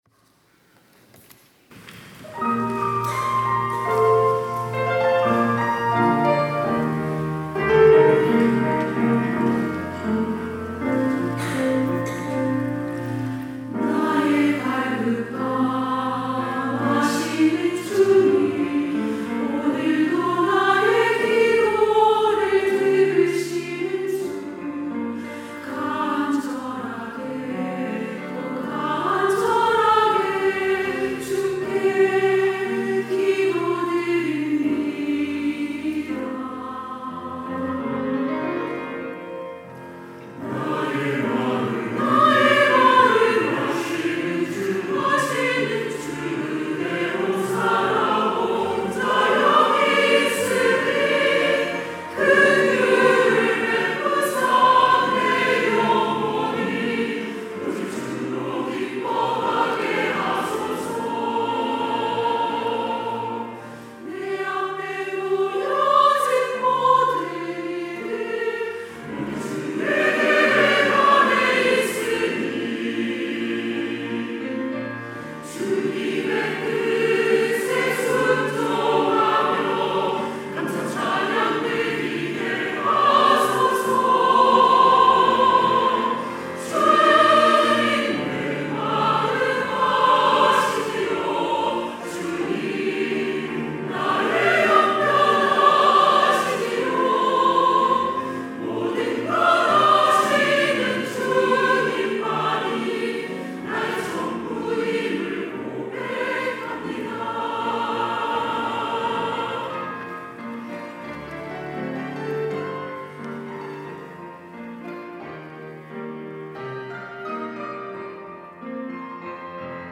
할렐루야(주일2부) - 주님만이 나의 전부입니다
찬양대